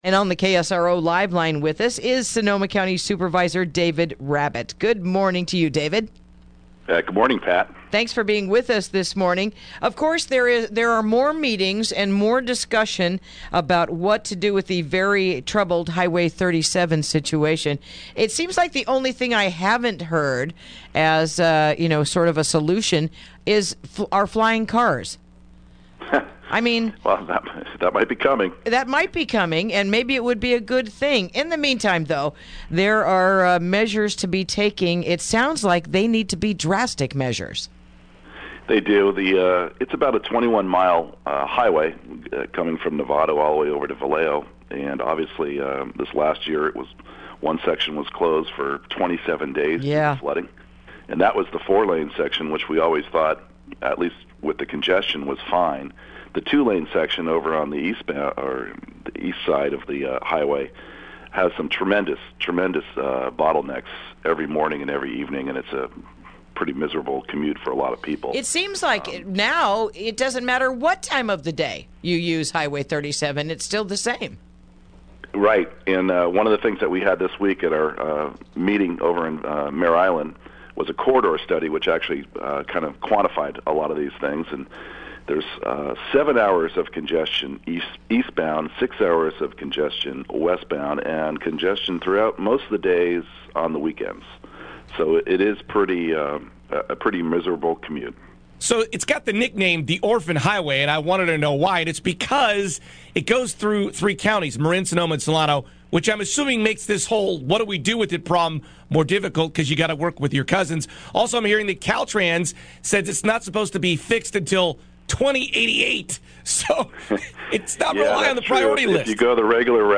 Interview: Highway 37 Upcoming Meetings
Supervisor David Rabbitt, joins us to talk about highway 37.